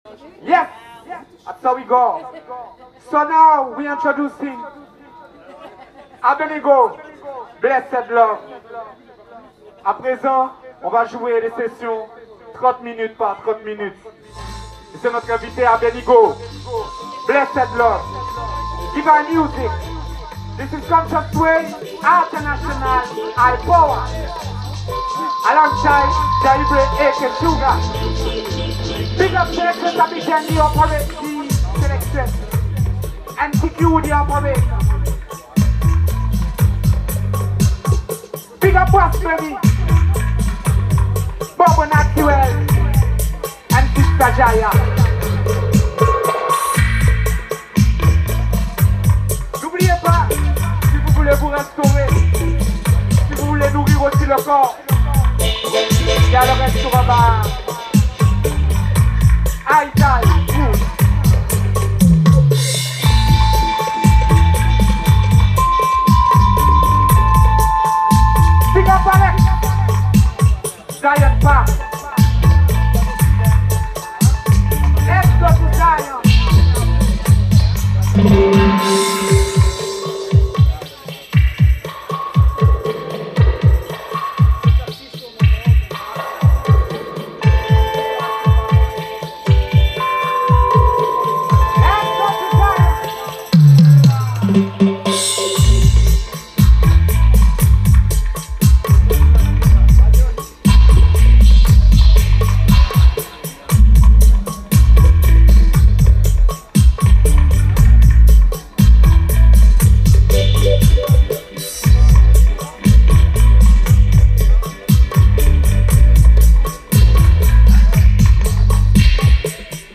Sound System Session, Cultural vibration in the Caribean island of Gwadeloup.
playing unreleased tunes from the label, dubplates and fresh releases
All MCs and players of instrument from CWO took part on every one selection in perfect love and inity.